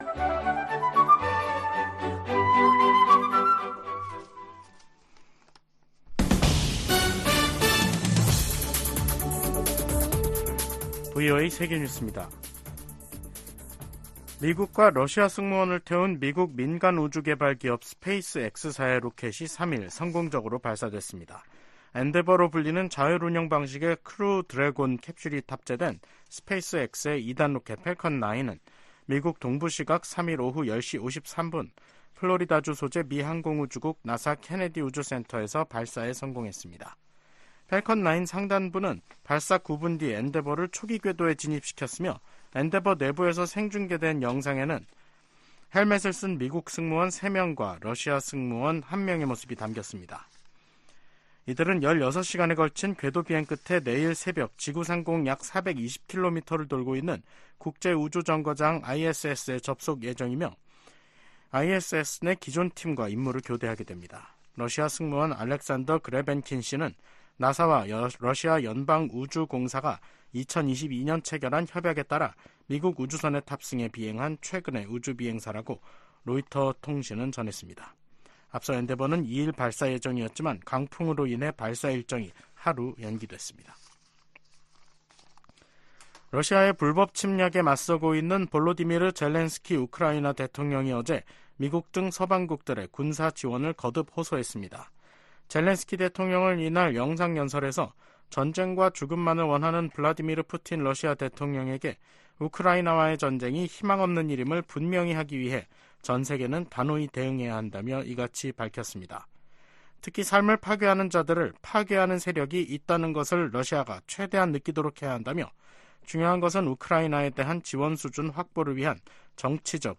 VOA 한국어 간판 뉴스 프로그램 '뉴스 투데이', 2024년 3월 4일 3부 방송입니다. 백악관 고위 관리가 한반도의 완전한 비핵화 정책 목표에 변함이 없다면서도 '중간 조치'가 있을 수 있다고 말했습니다. 유엔 안전보장이사회 순회 의장국 일본이 북한 핵 문제에 대한 국제적 대응에 나설 것이라고 밝혔습니다.